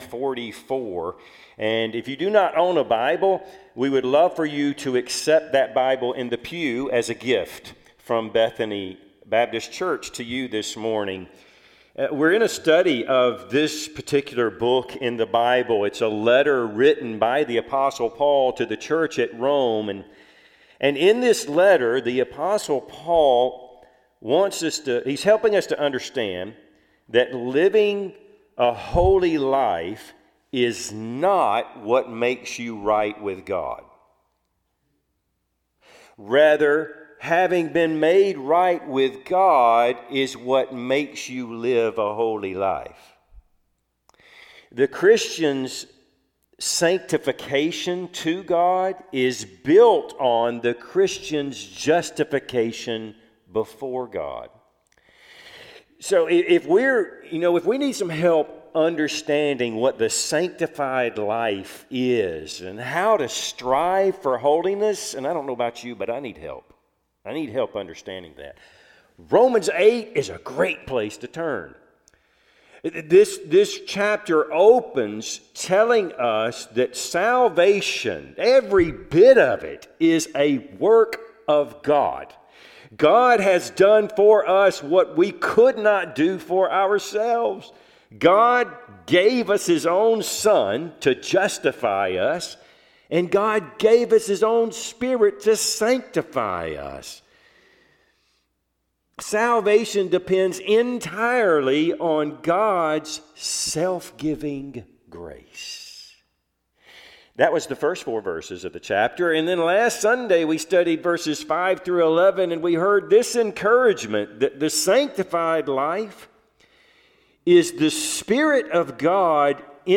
Passage: Romans 8:12-17 Service Type: Sunday AM